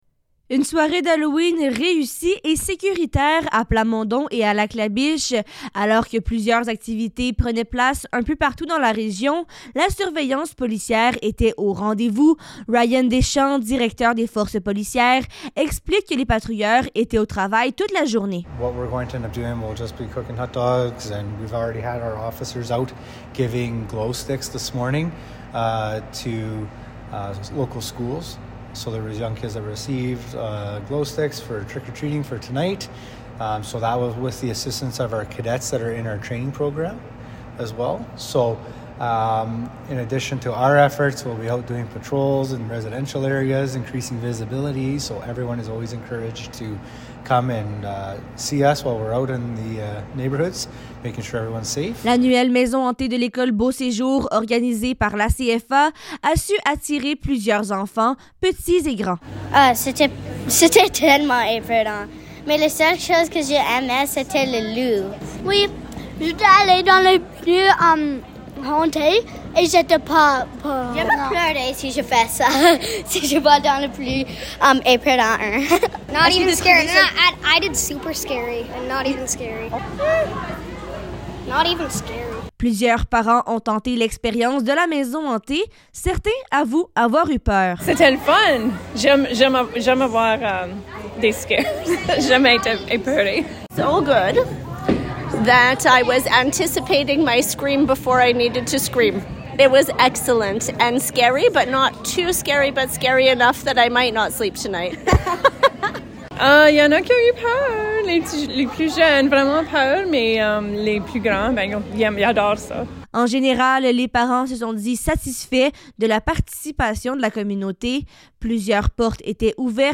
Halloween-Vox-Pop-Reportage.mp3